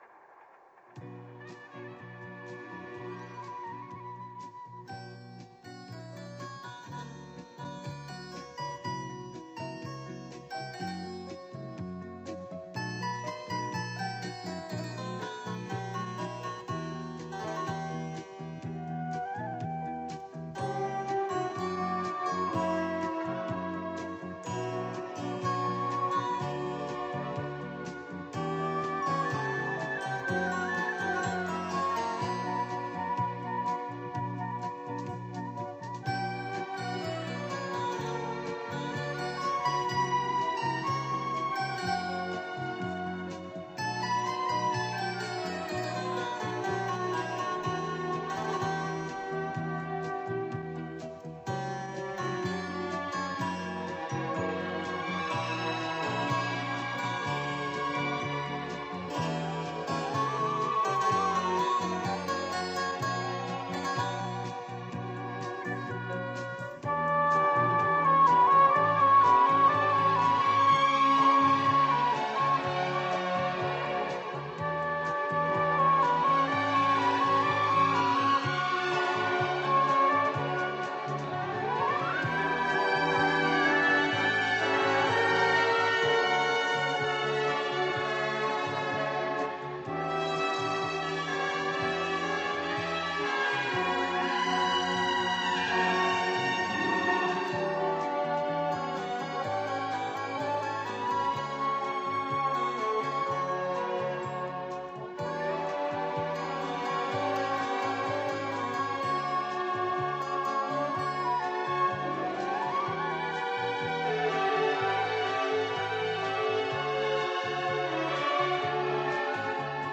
Genre:Jazz
Style:Easy Listening